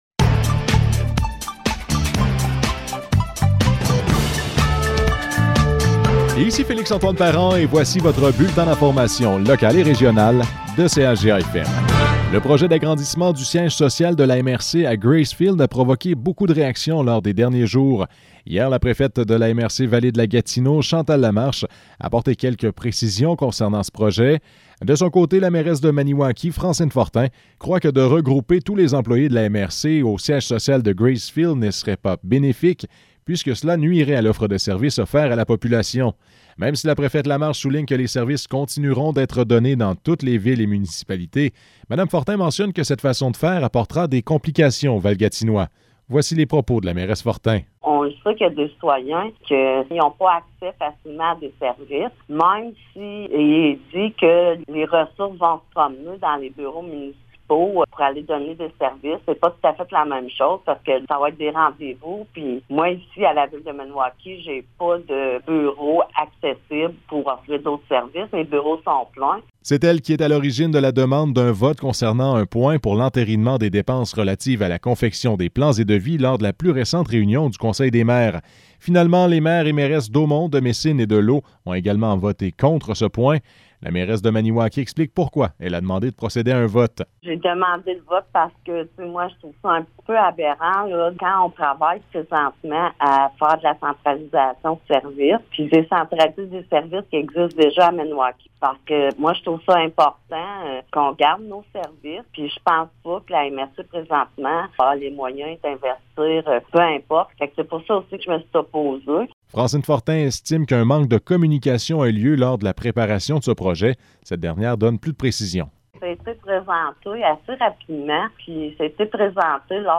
Nouvelles locales - 23 juin 2022 - 12 h